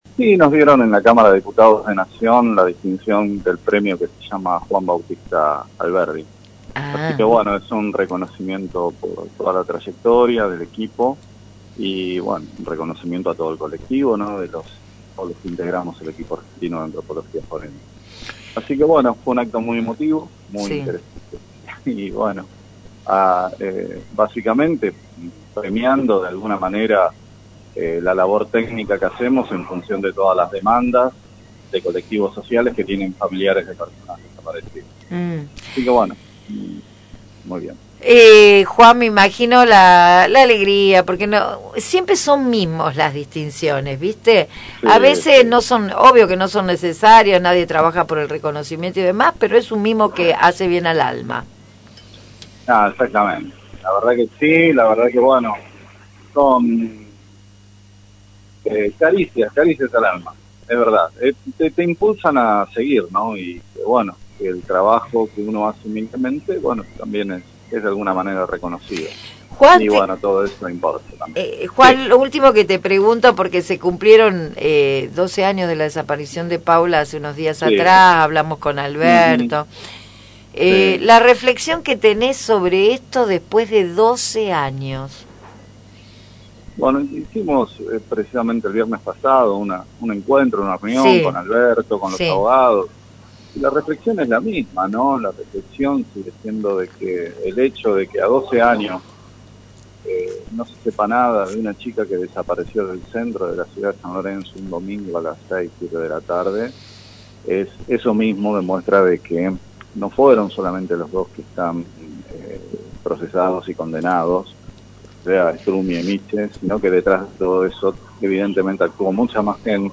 antropólogo y perteneciente al Equipo en diálogo con «Un día de Gloria»